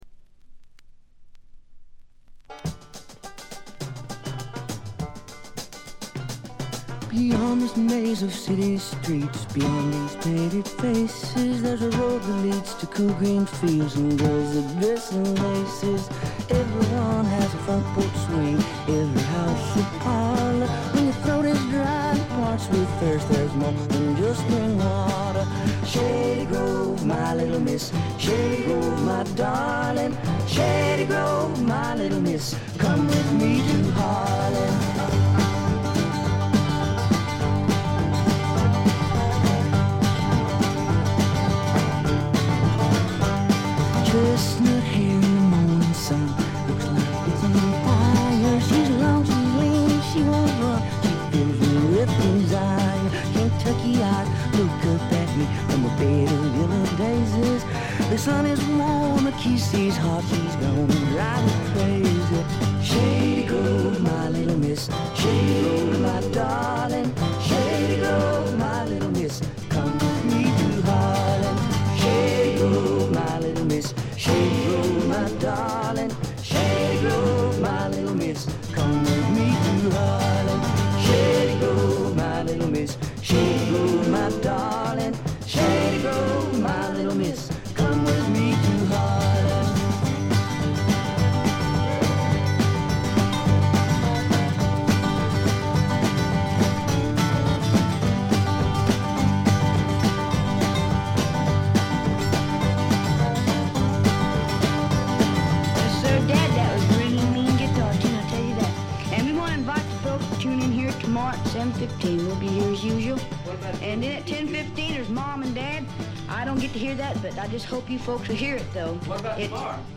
ところどころでチリプチ。
試聴曲は現品からの取り込み音源です。